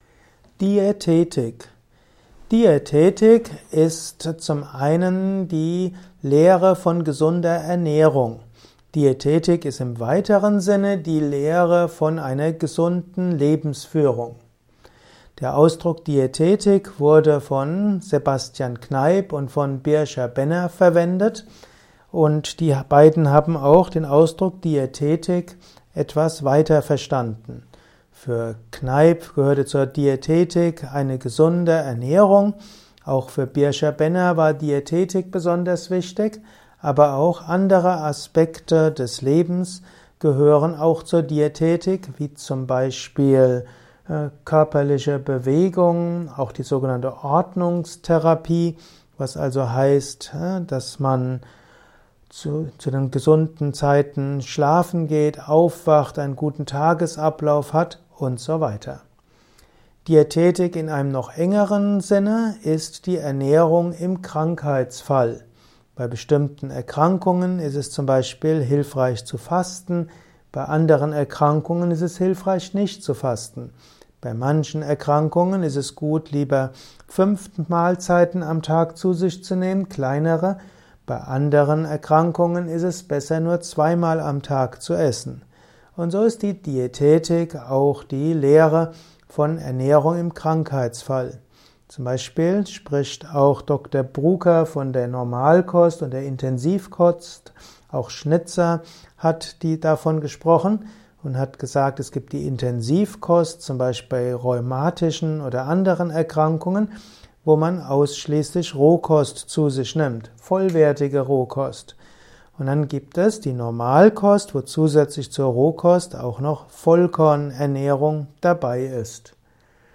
Ein Kurzvortrag über den Begriff